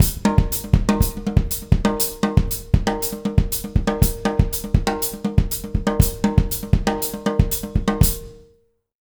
120SALSA05-R.wav